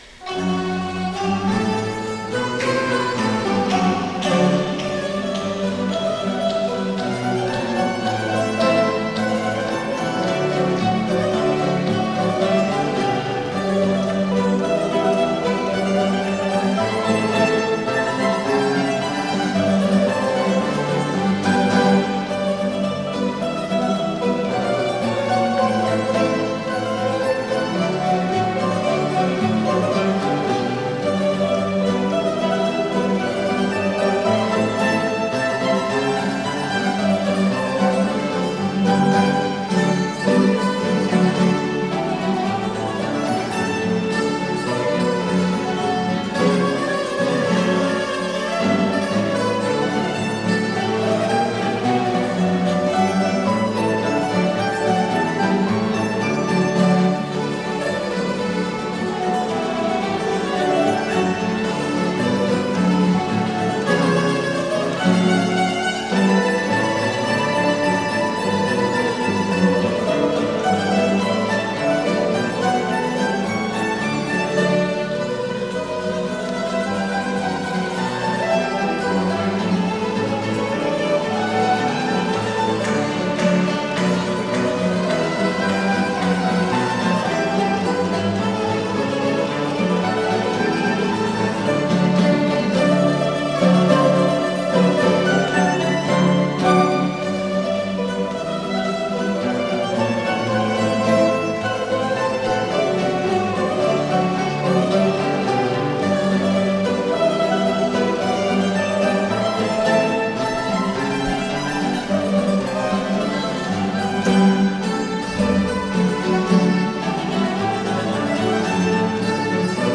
polka.mp3